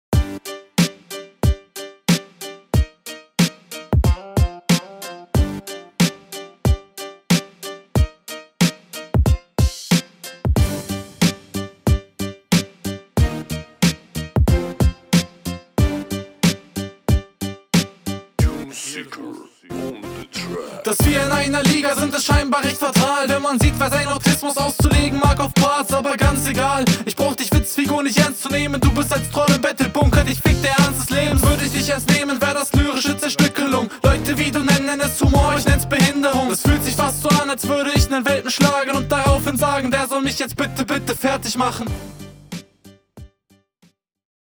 Flow ist ein Stück stärker als in deiner RR, einfach weil es druckvoller klingt.
Auch richtig geiler Beat